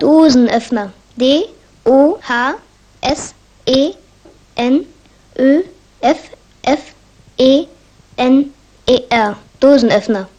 cb-m1-boy4.mp3